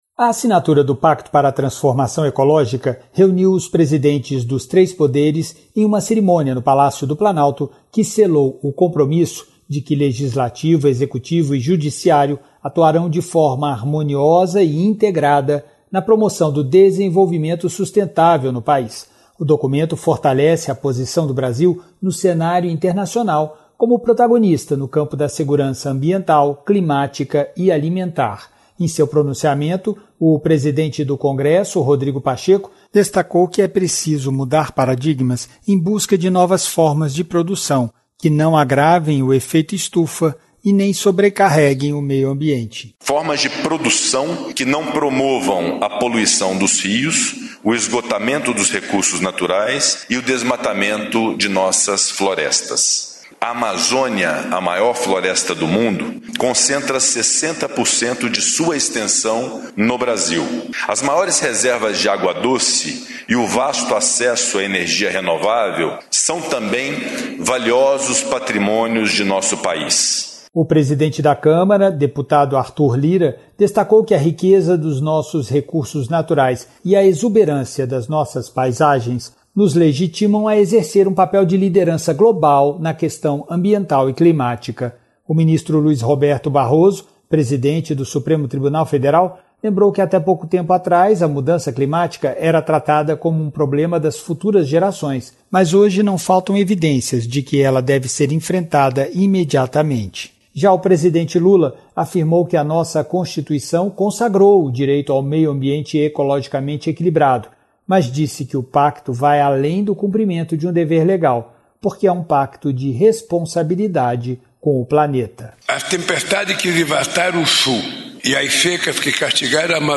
Pronunciamento